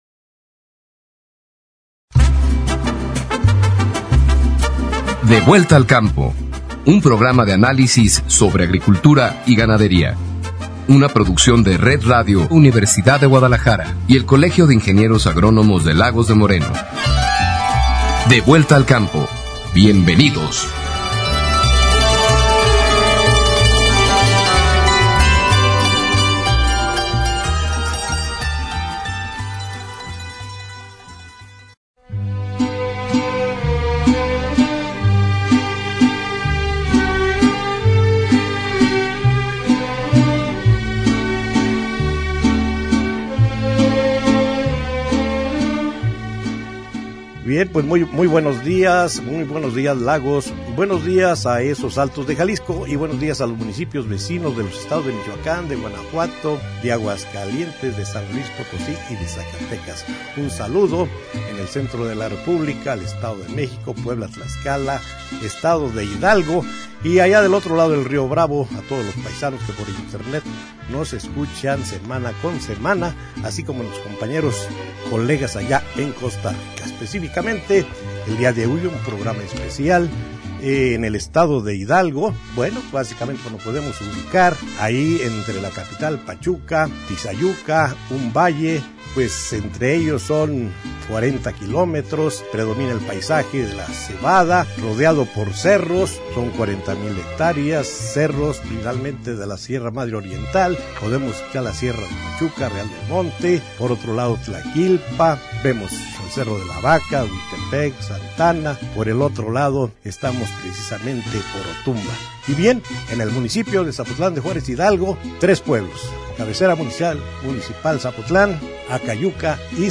GÉNERO: De opinión